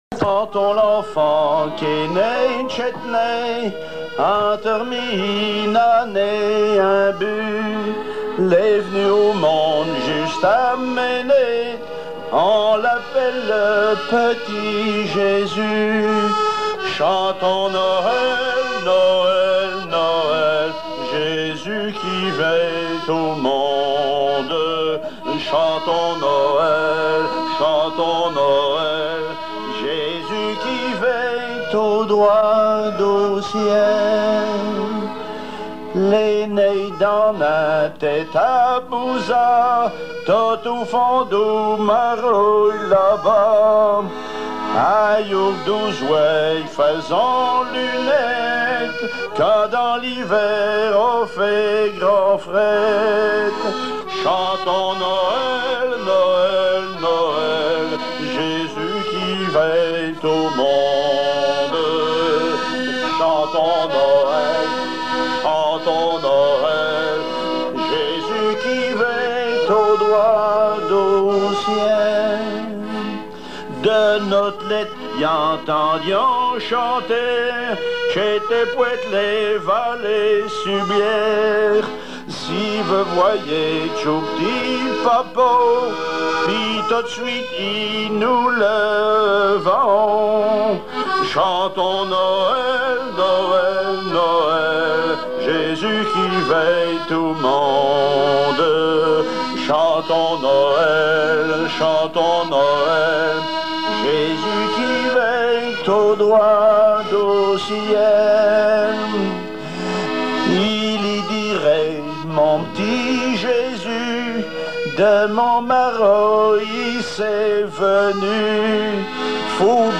Genre strophique
émission La fin de la Rabinaïe sur Alouette
Pièce musicale inédite